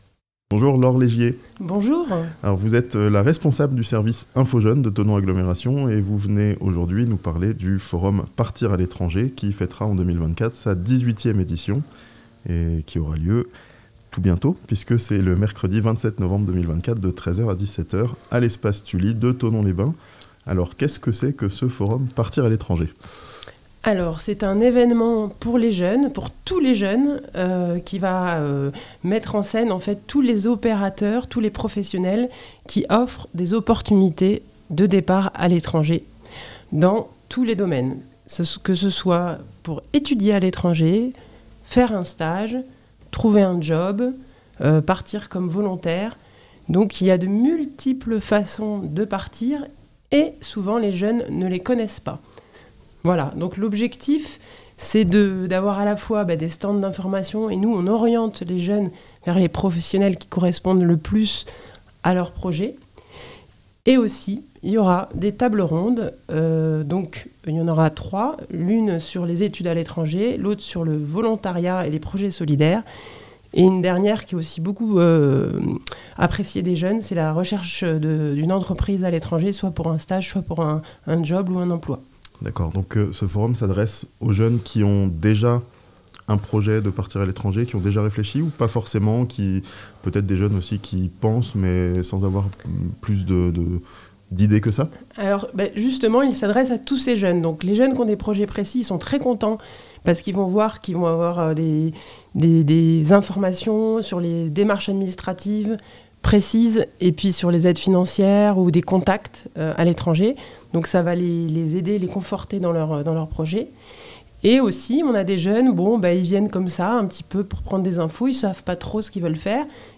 A Thonon, un forum ce mercredi pour aider les jeunes à partir à l'étranger (interview)